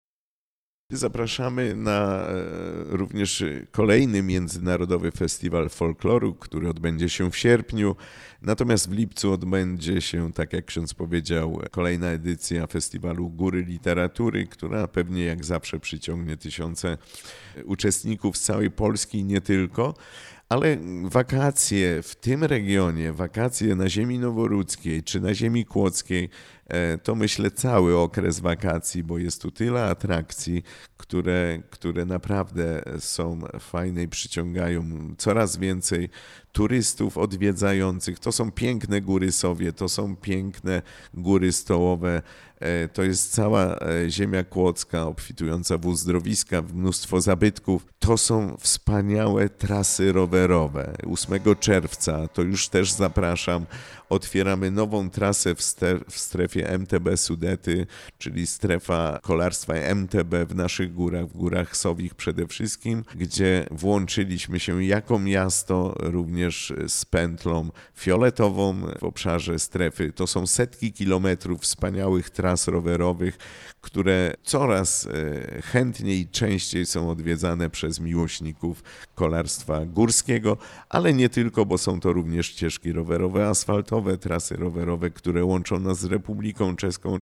Na zakończenie burmistrz zaprosił do odwiedzenia Nowej Rudy i odkrycia jej sportowego, turystycznego i rekreacyjnego potencjału. Szczególnie zachęcił do udziału w wakacyjnych wydarzeniach: Festiwalu Góry Literatury (4–12 lipca) oraz Międzynarodowym Festiwalu Folkloru (7–11 sierpnia):